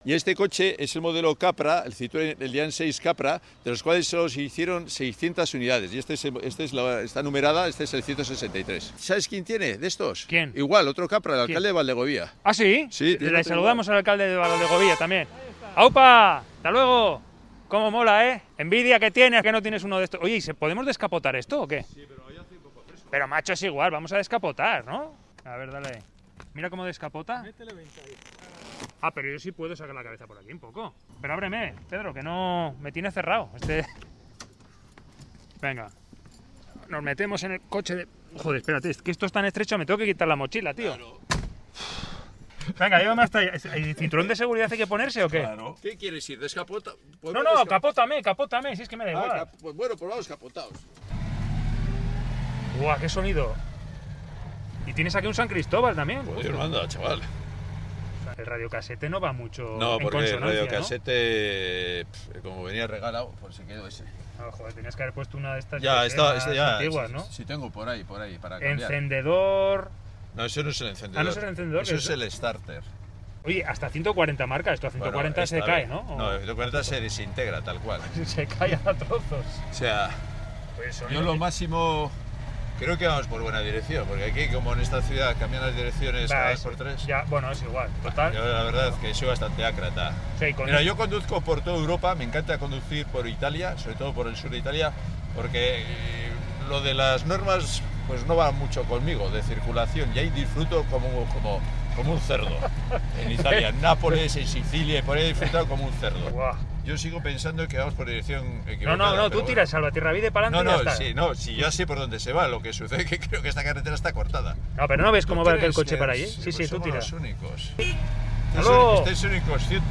Audio: Reportaje: 'Slow driving'.